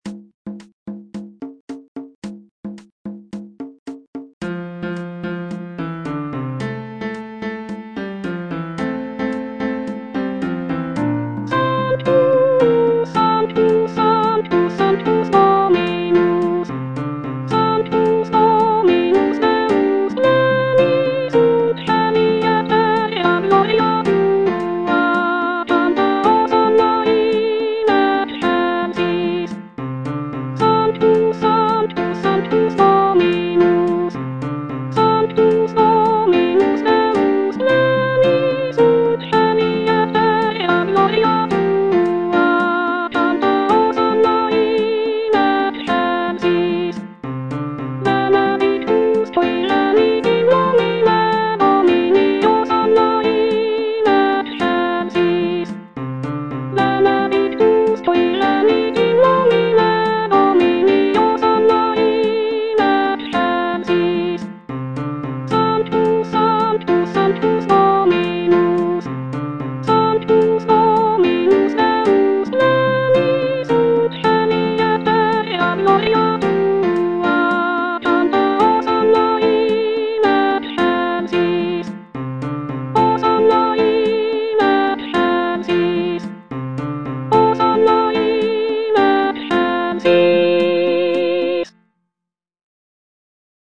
Soprano (Voice with metronome) Ads stop